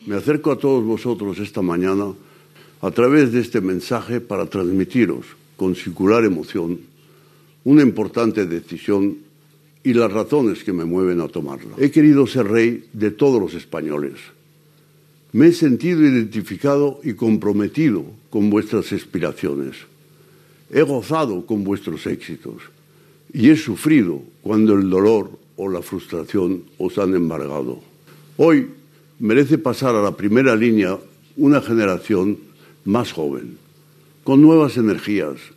Discurs del rei Juan Carlos I que exposa els motius pels quals ha decidit abdicar